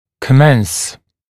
[kə’mens][кэ’мэнс]начинать, начинаться